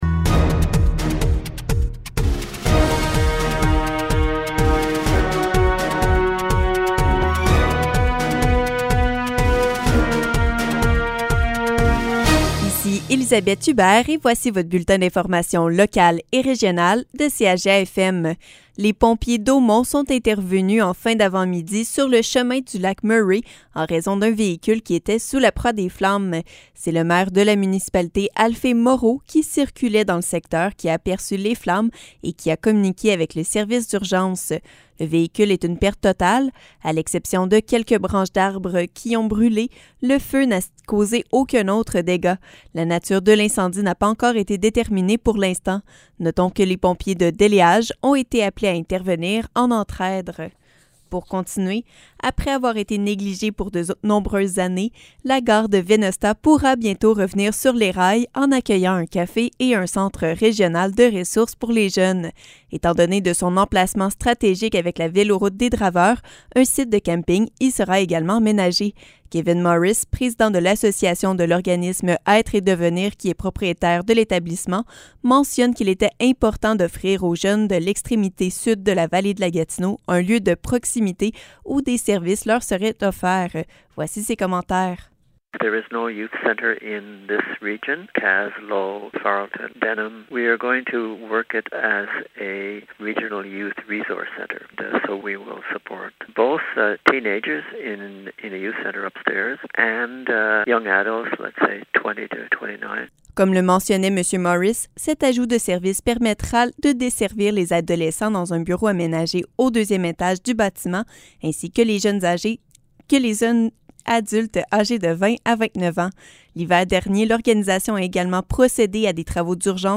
Nouvelles locales - 17 août 2021 - 15 h